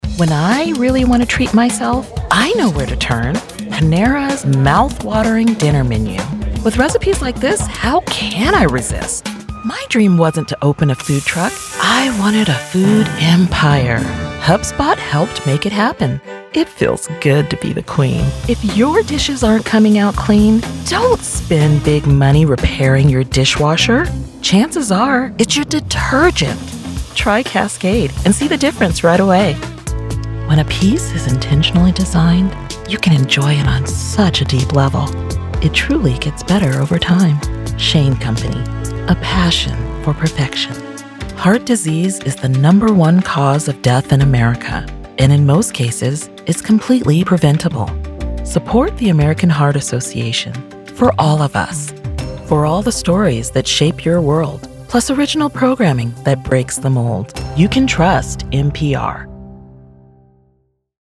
Commercial Voiceover
Compelling and persuasive vocal performances tailored specifically for high-impact television and radio advertisements.
Every audition and final track is recorded in professional-grade studios for pristine clarity.